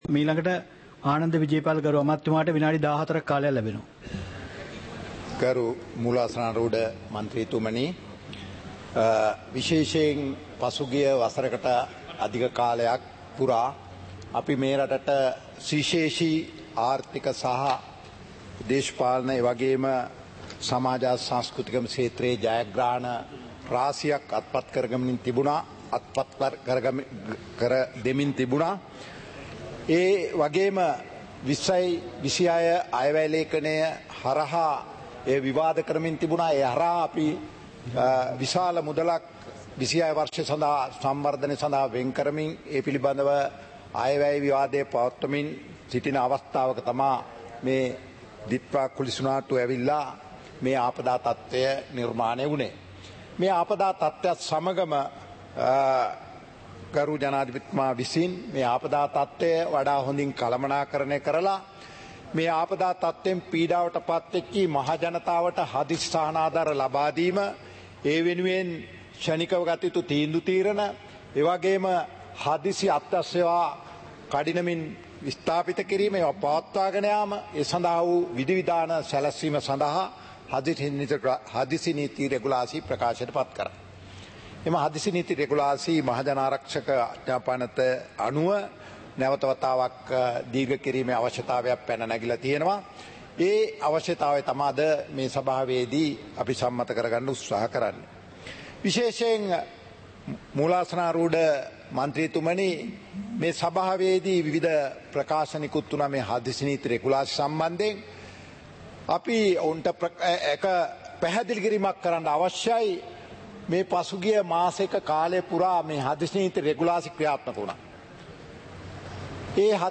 பாராளுமன்ற நடப்பு - பதிவுருத்தப்பட்ட